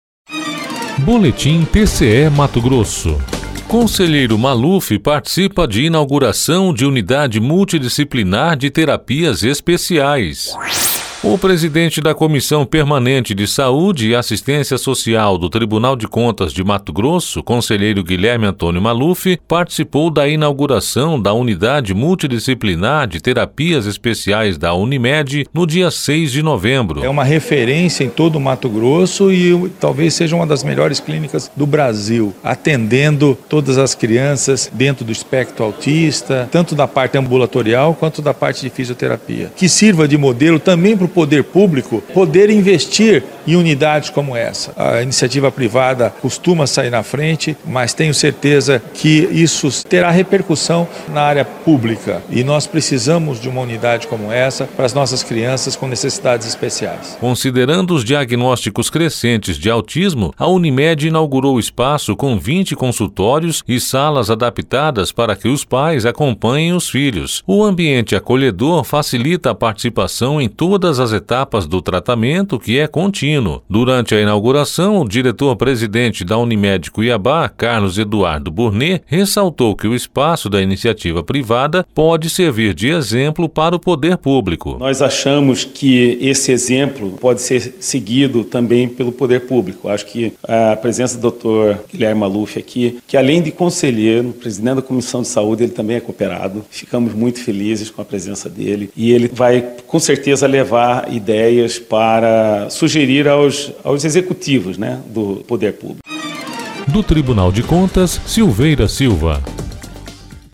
Sonora: Guilherme Antonio Maluf – conselheiro presidente da CPSAS do TCE-MT